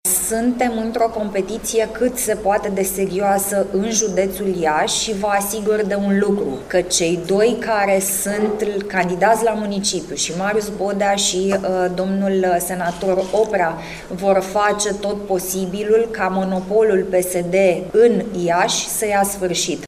În prezența co-președinților PNL, Alina Gorghiu și Vasile Blaga, astăzi, la Iași, a avut loc lansarea candidaților pentru funcțiile de primari și consilieri locali și județeni.
Marius Bodea este candidatul liberal pentru funcția de primar al Iașului, iar senatorul Dumitru Oprea va deschide lista de candidați liberali pentru Consiliul Județean Iași, a precizat Alina Ghorghiu, copreședinte PNL: